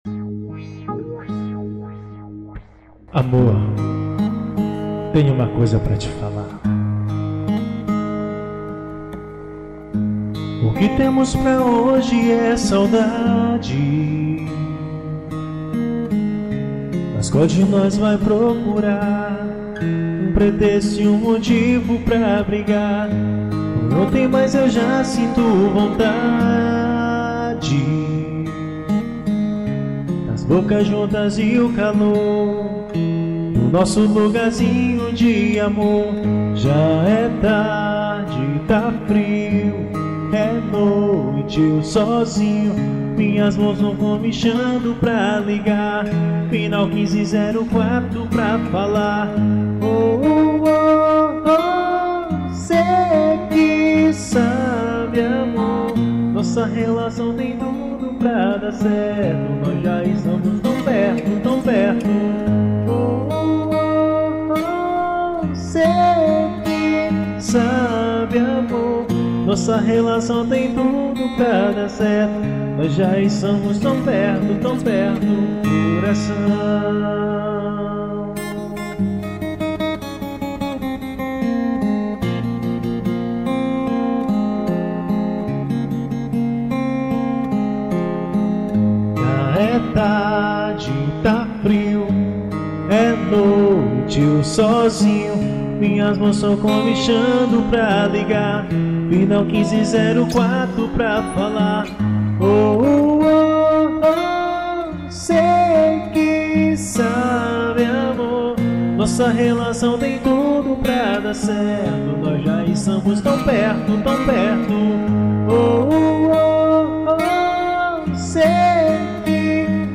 Sertanejo